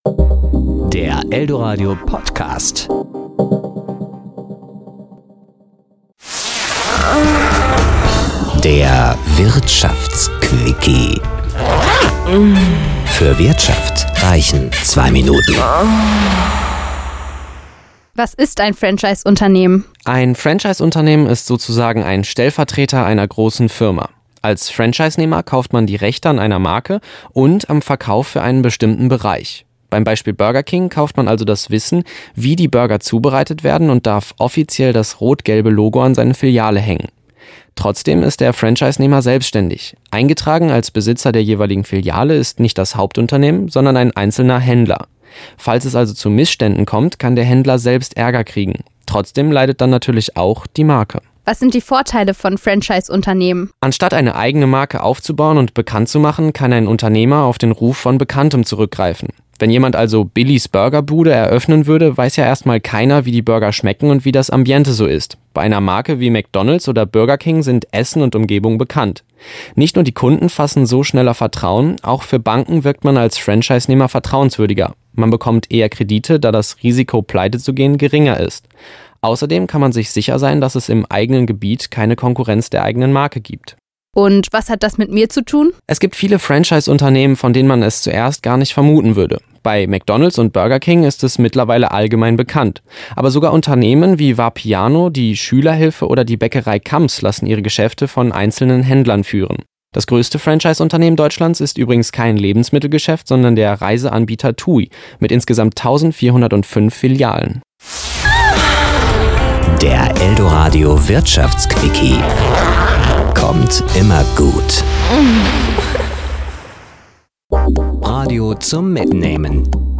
Serie: Beiträge